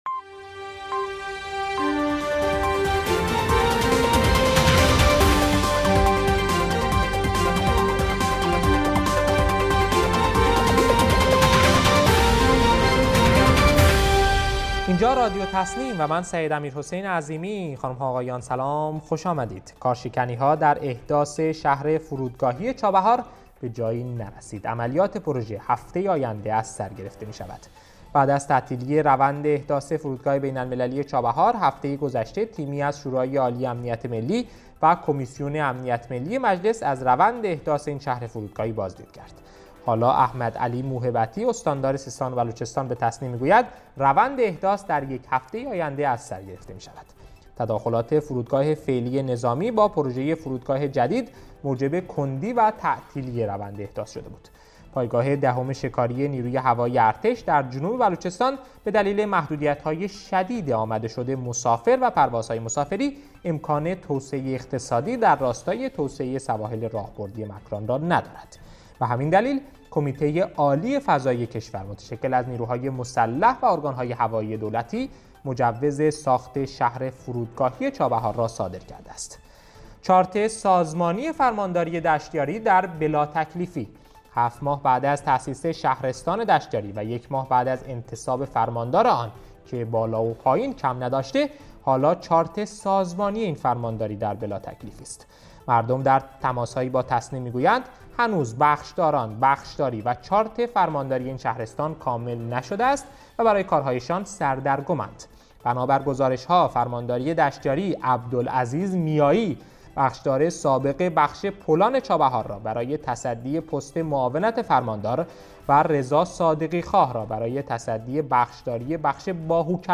گروه استان‌ها- در هشتمین بسته خبری رادیو تسنیم سیستان و بلوچستان با مهم‌ترین عناوین خبری روز همراه ما باشید.